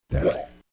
Category: Sound FX   Right: Personal
Tags: humor funny sound effects sound bites radio